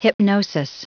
Prononciation du mot hypnosis en anglais (fichier audio)
Prononciation du mot : hypnosis
hypnosis.wav